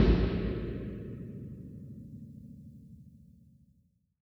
Index of /musicradar/layering-samples/Drum_Bits/Verb_Tails